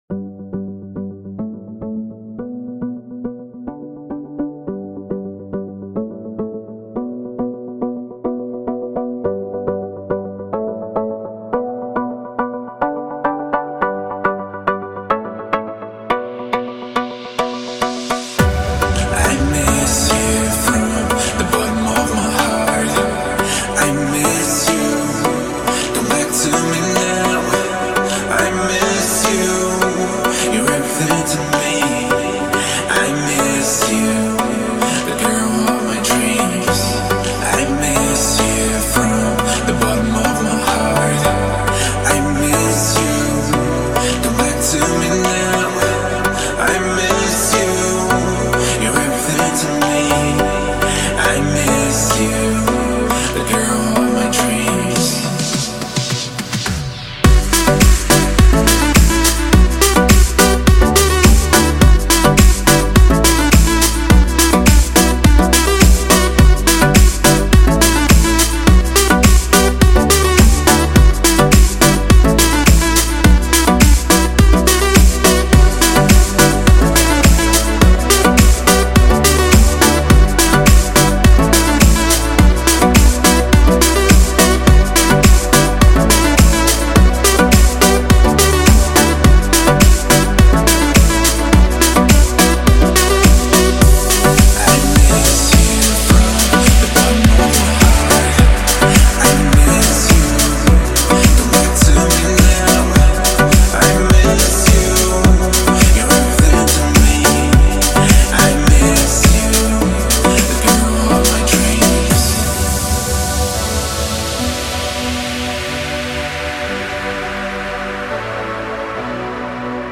это трек в жанре поп с элементами электронной музыки
вокалисткой